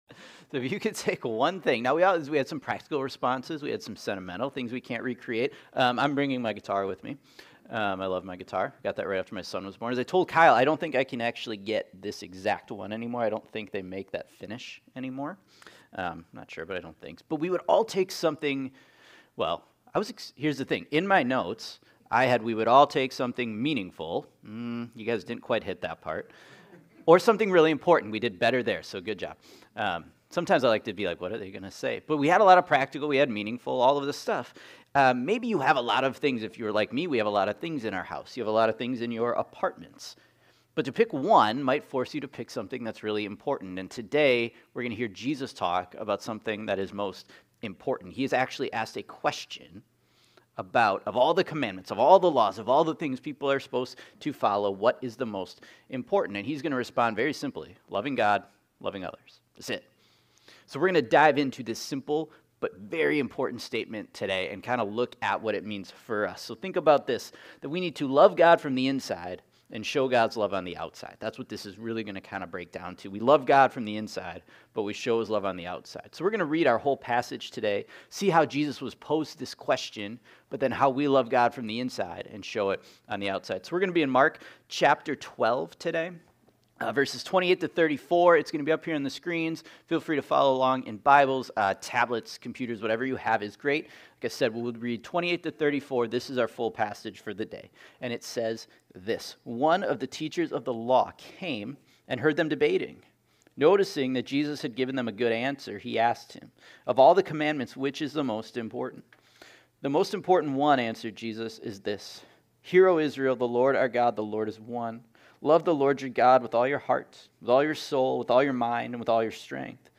Mark Confusion Heart Love Mind Neighbor Pressure Questions Soul Strength Sunday Morning Jesus was asked a genuine question, by a teacher of the law, about which commandment is the greatest and most important.